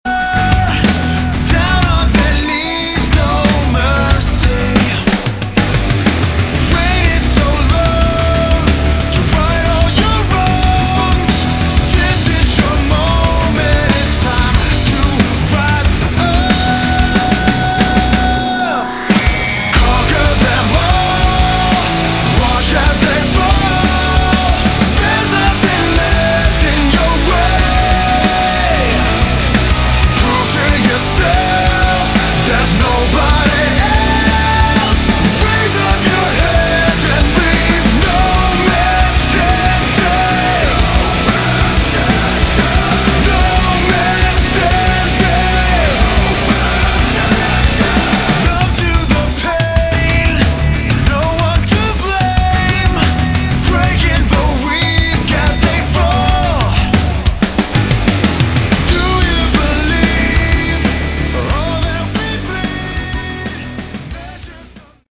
Category: Modern Hard Rock
lead guitar, vocals
drums
bass